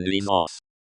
nuisance, as they say in French) for the two of us.